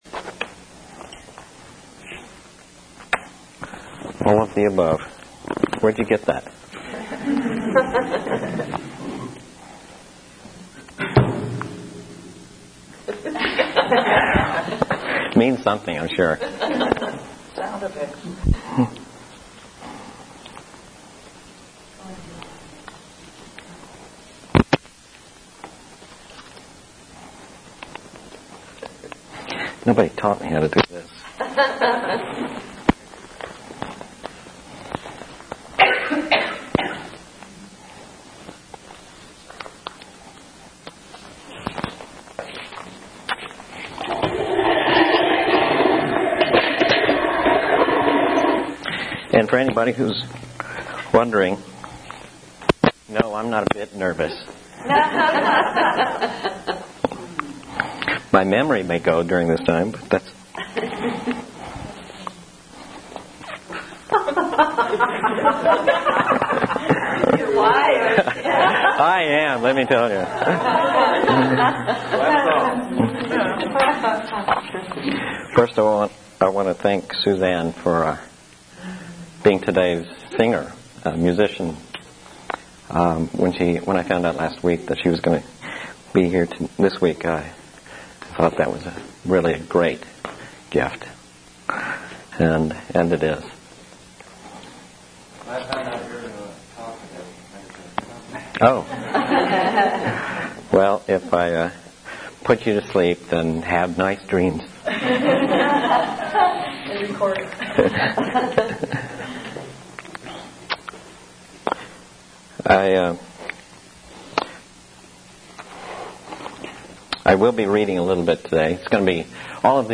They are of presentations and workshops I’ve given covering various subjects.